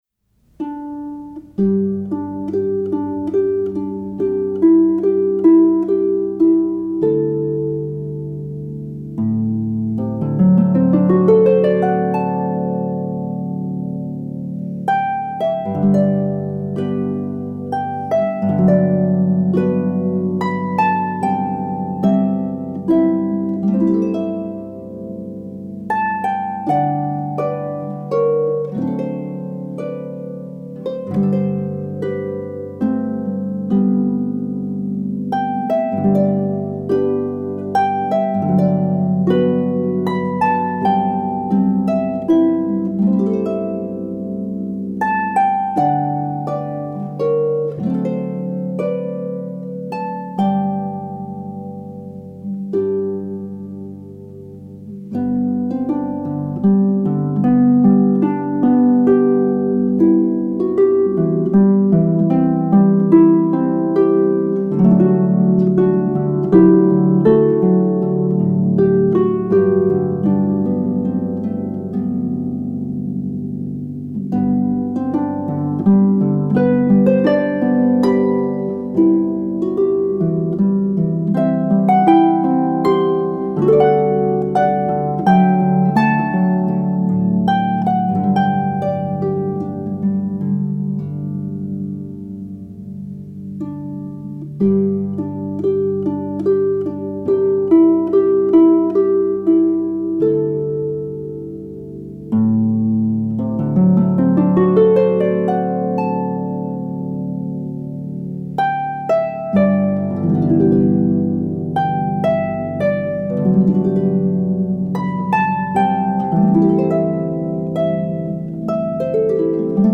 is for solo pedal harp